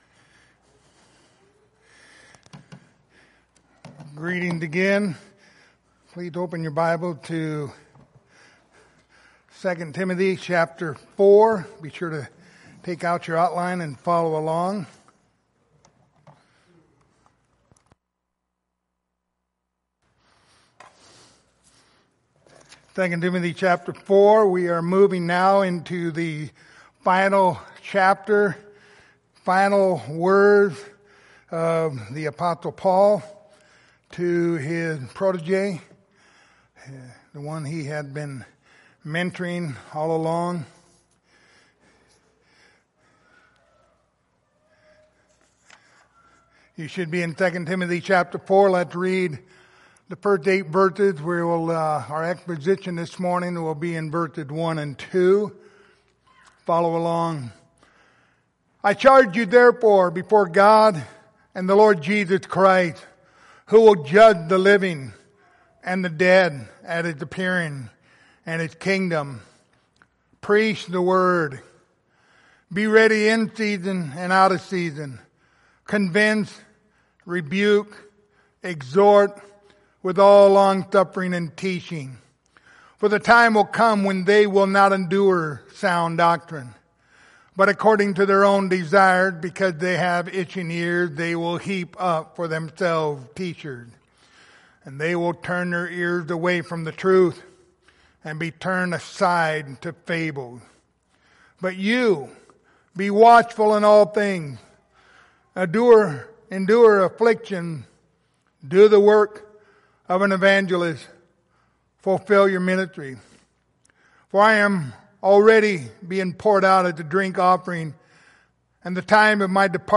Passage: 2 Timothy 4:1-2 Service Type: Sunday Morning